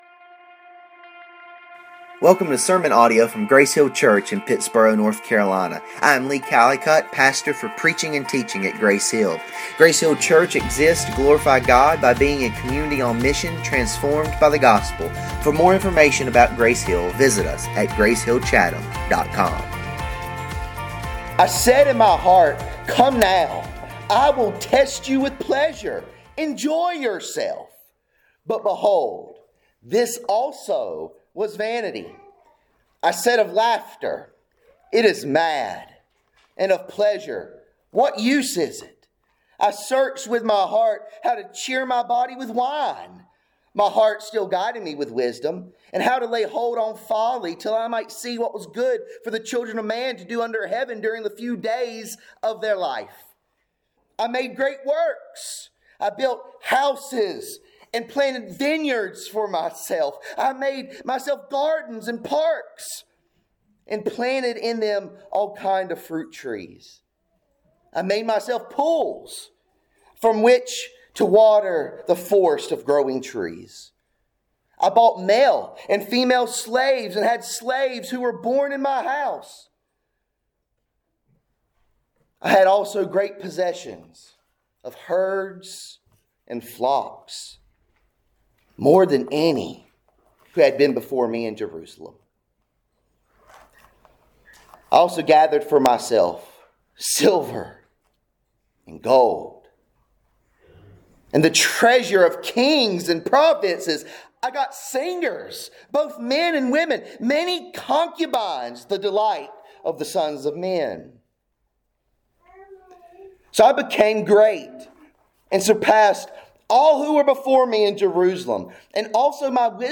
In this sermon, we explore Ecclesiastes 2:1-11, where Solomon embarks on a quest to find meaning in pleasure. Despite indulging in every conceivable delight—laughter, wine, grand projects, and wealth—he concludes that all is vanity, a chasing after the wind.